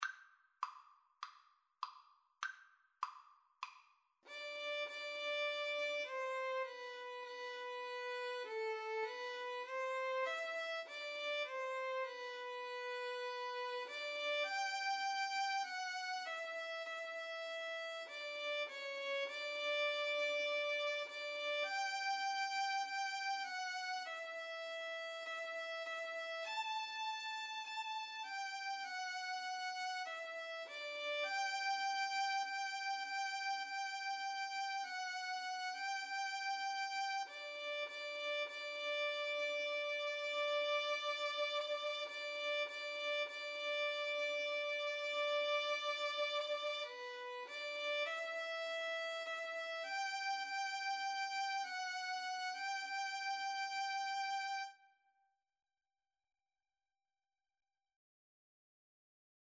Free Sheet music for Violin-Guitar Duet
G major (Sounding Pitch) (View more G major Music for Violin-Guitar Duet )
4/4 (View more 4/4 Music)
Classical (View more Classical Violin-Guitar Duet Music)